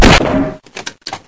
glauncher1.ogg